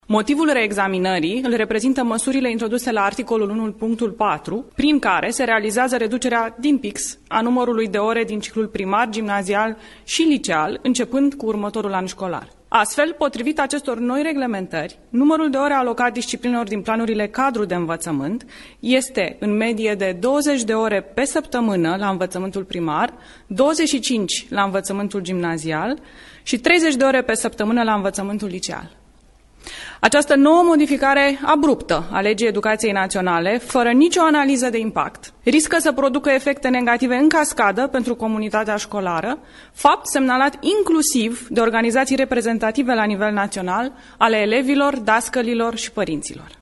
Preşedintele Klaus Iohannis solicită Parlamentului reexaminarea actului normativ care modifică Legea Educaţiei în sensul reducerii numărului de ore, a anunţat joi, 3 ianuarie 2019, într-o declarație de presă, Ligia Deca, consilier de stat în cadrul Departamentului Educaţie şi Cercetare al Administraţiei Prezidenţiale.